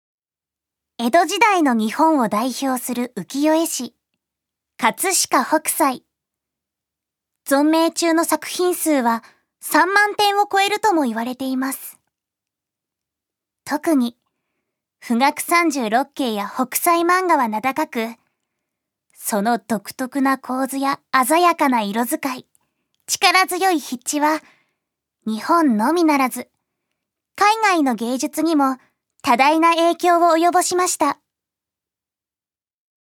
ジュニア：女性
ナレーション１